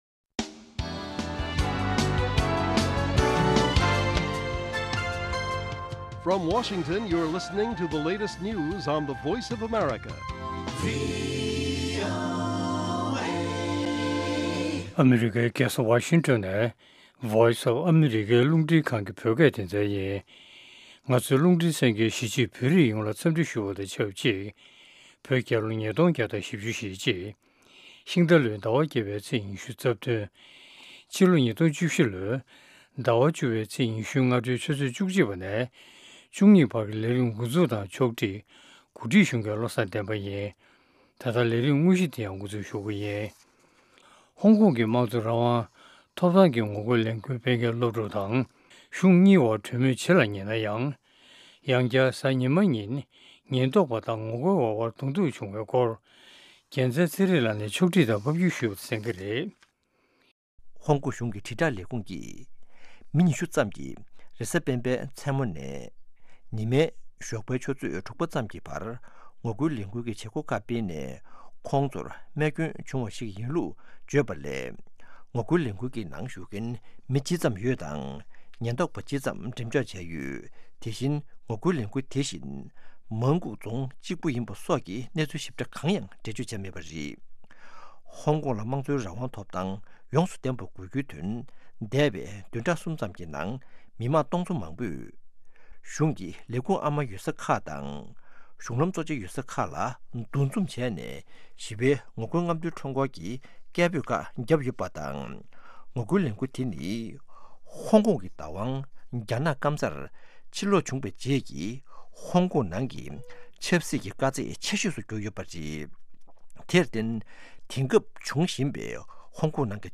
Afternoon News